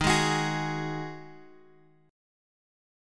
D#mbb5 chord {x 6 6 x 7 4} chord
Dsharp-Minor Double Flat 5th-Dsharp-x,6,6,x,7,4.m4a